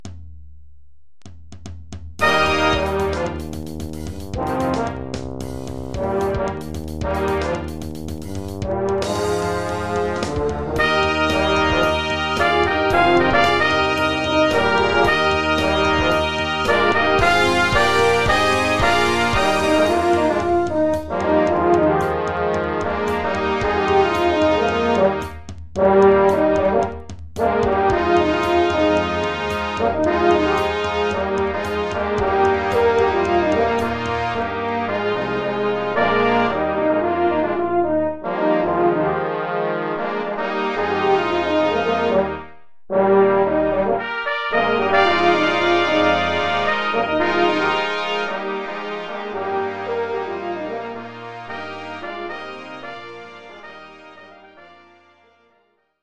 Niveau de difficulté : Moyen avancé
Collection : Batterie-fanfare
Oeuvre pour orchestre de
batterie-fanfare.